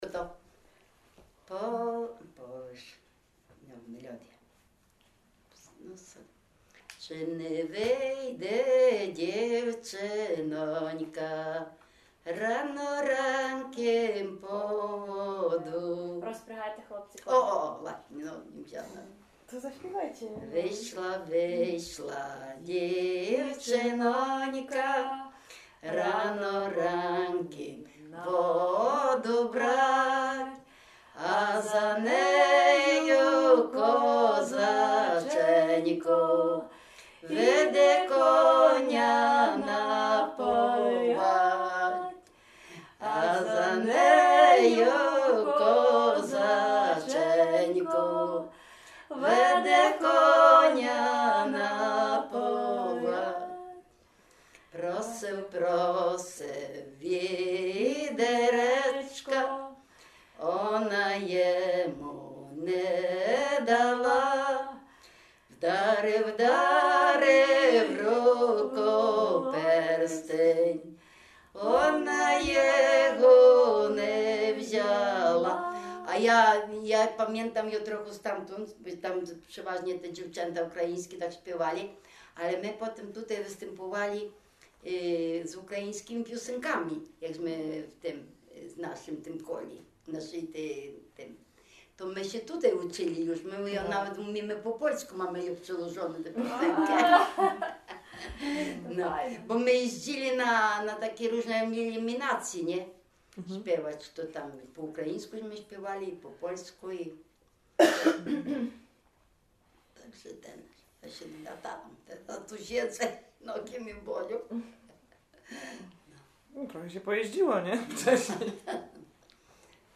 W wymowie Ł wymawiane jako przedniojęzykowo-zębowe; e (é) w końcu wyrazu zachowało jego dawną realizację jako i(y)
genre Na Przywody